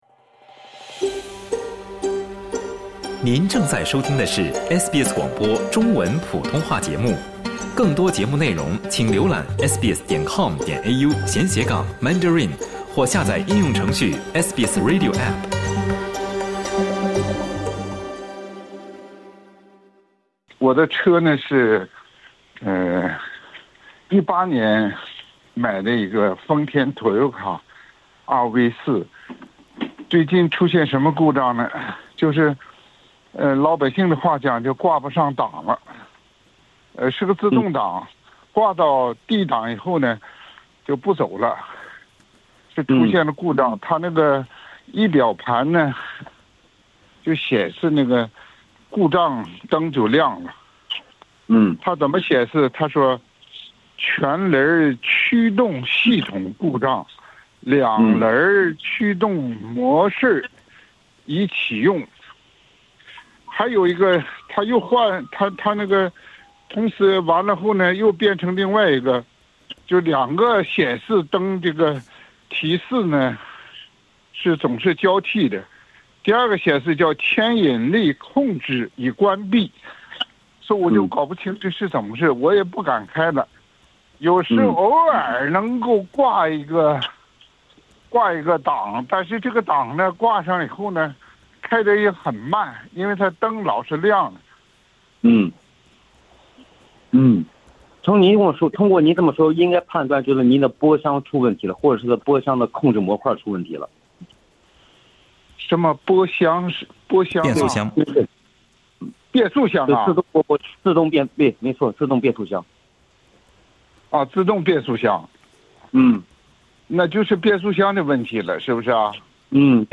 SBS普通话节目的一位听友咨询，他的自动挡汽车挂D挡后不行驶。就此，汽车专家分析了可能原因。